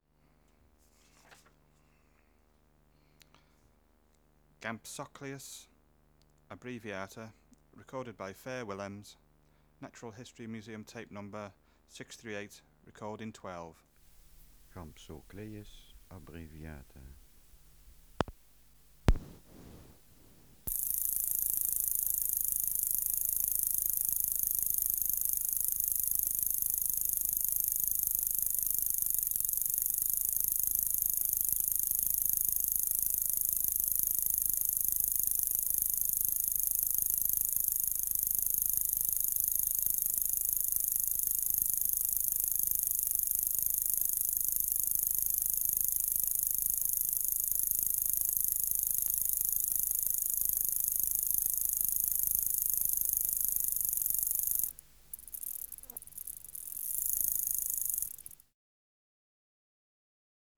Extraneous Noise: Birds, flies Substrate/Cage: In cage Biotic Factors / Experimental Conditions: Isolated male
Microphone & Power Supply: AKG D202E (LF circuit off) Distance from Subject (cm): 15
Recorder: Uher 4200